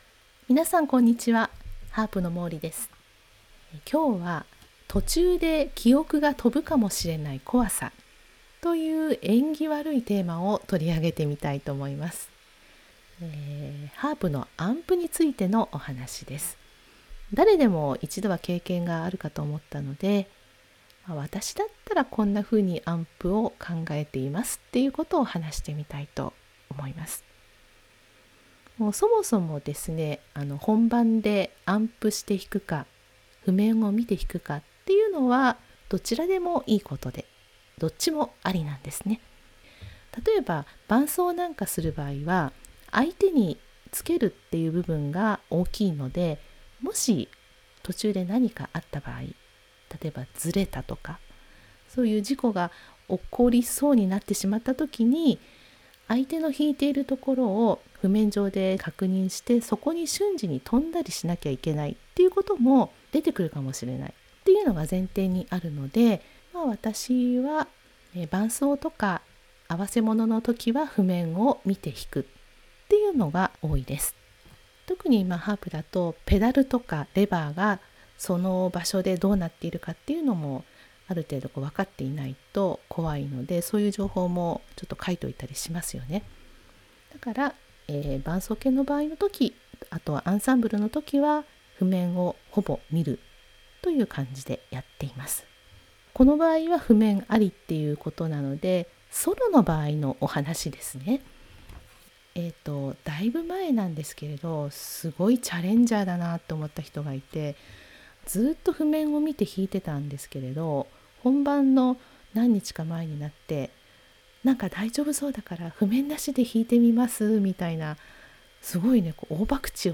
（音声ブログ）途中で記憶が飛ぶかもしれない怖さ